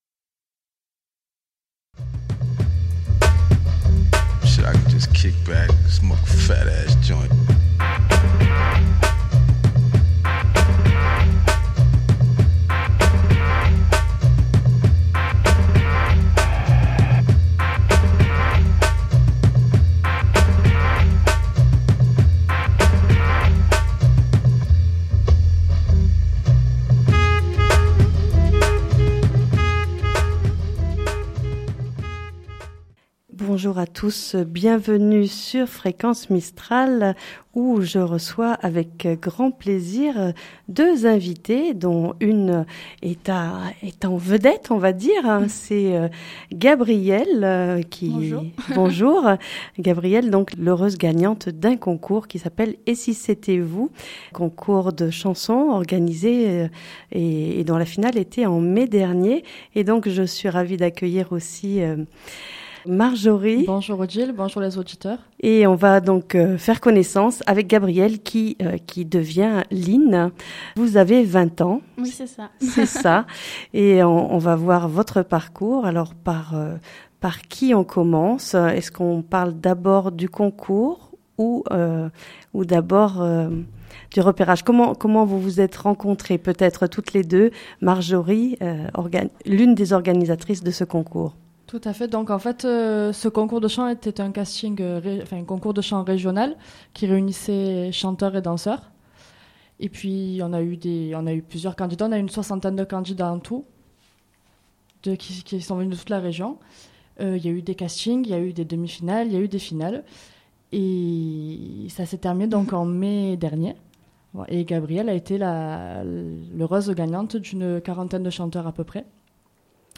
une émission préparée, présentée et animée